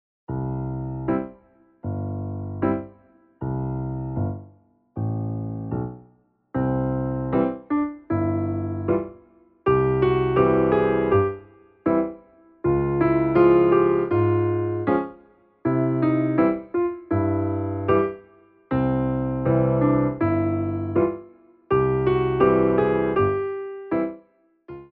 Piano Arrangements of Pop & Rock for Tap Class
SLOW TEMPO